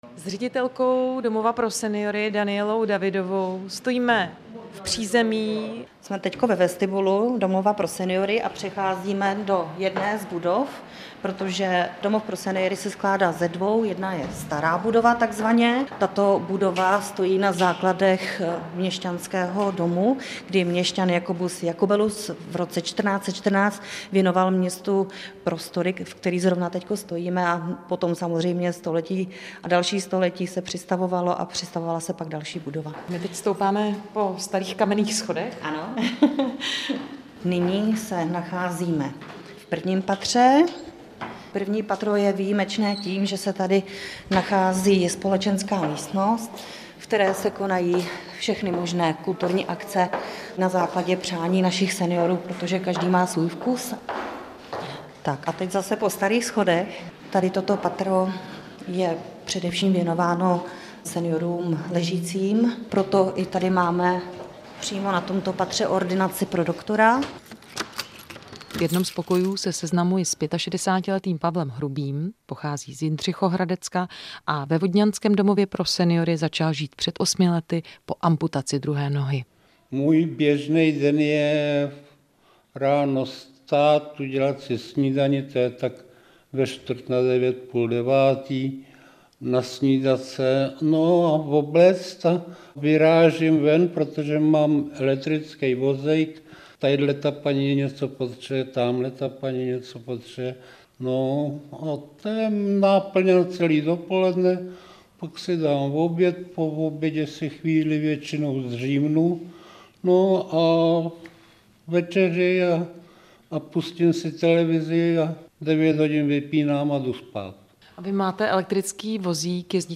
Reportáž v Českém rozhlasu